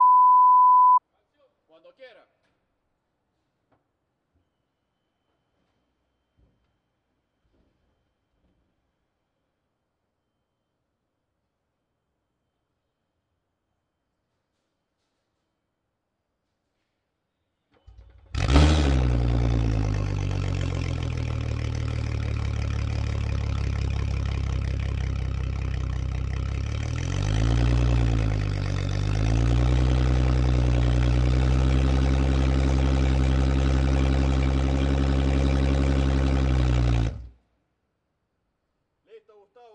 船舷外发动机减速和停止
描述：船舷外发动机减速和停止。
标签： 舷外 雅马哈 海洋 停止 发动机
声道立体声